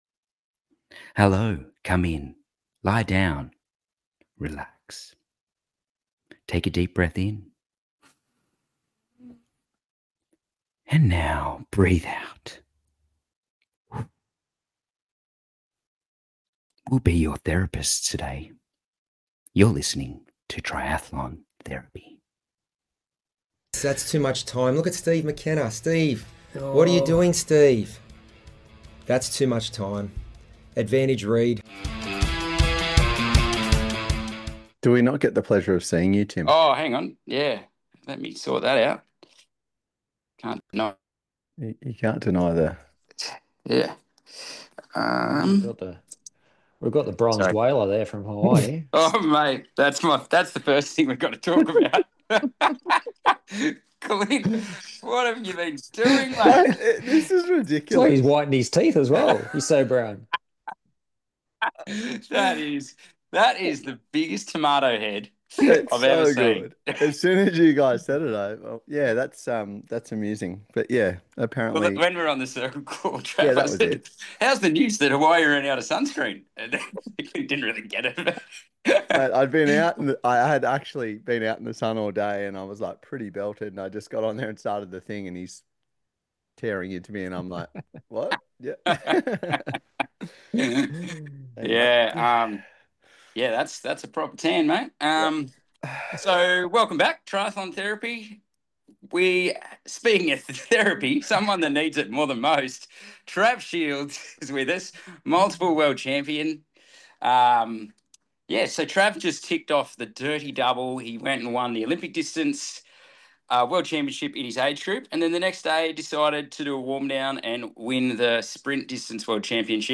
RPG Age Group athletes chat about recent races
A great chat with the coaches and athletes about their respective races!